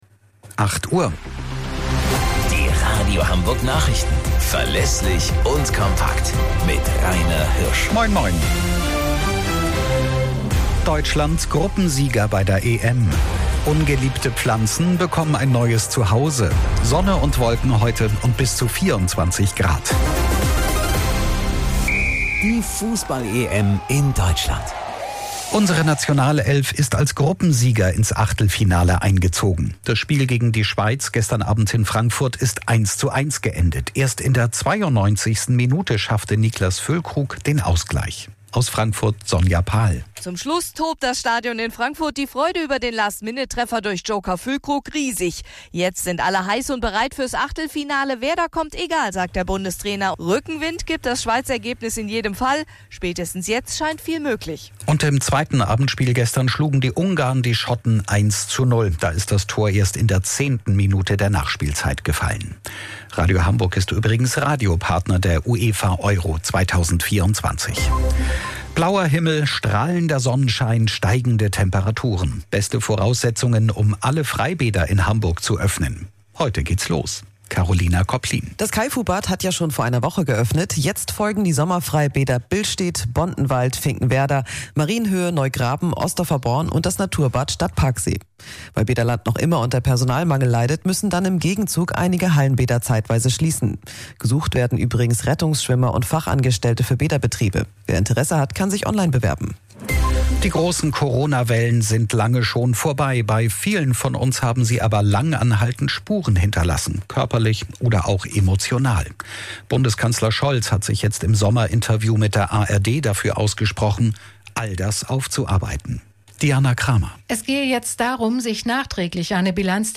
Radio Hamburg Nachrichten vom 24.06.2024 um 15 Uhr - 24.06.2024